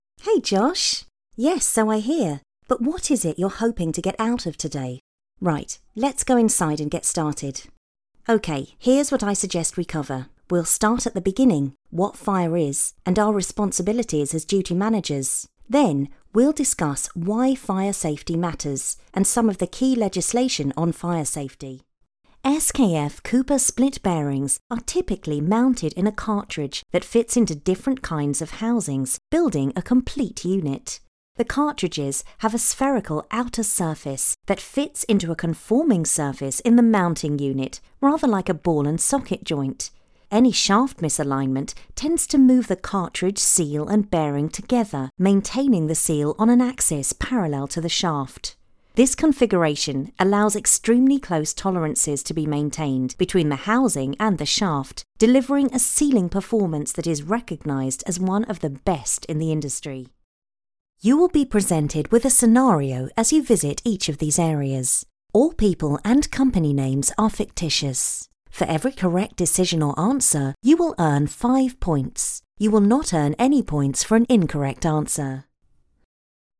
Engels (Brits)
Natuurlijk, Speels, Veelzijdig, Vriendelijk, Warm
Corporate